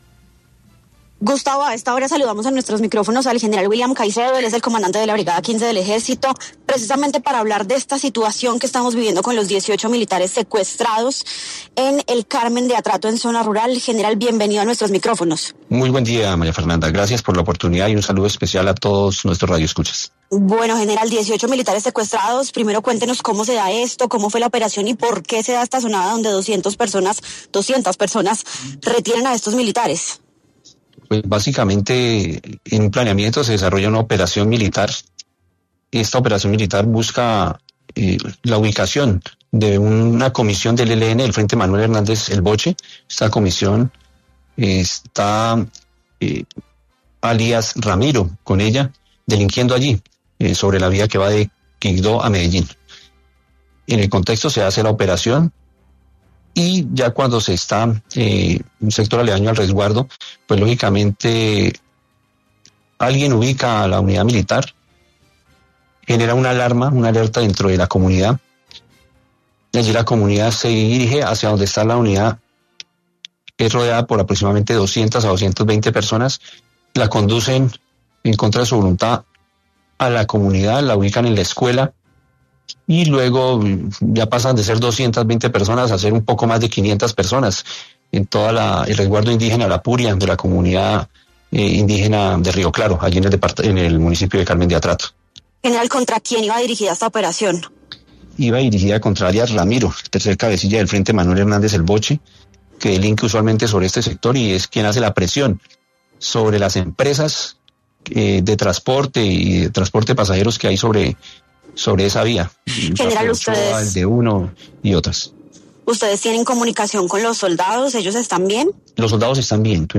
En 6AM de Caracol Radio estuvo el General William Caicedo, quien dio detalles de los 18 soldados secuestrados